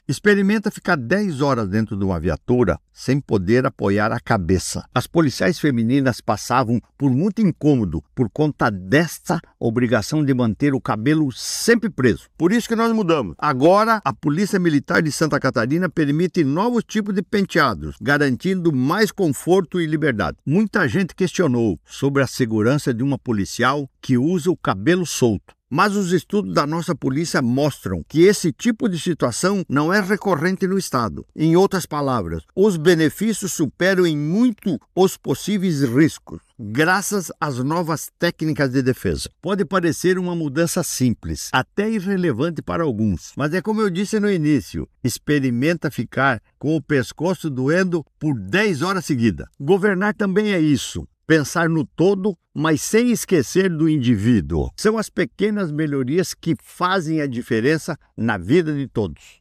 O governador Jorginho Mello comenta sobre a mudança:
SECOM-Sonora-Governador-Mudanca-Penteados-PM-Mulheres.mp3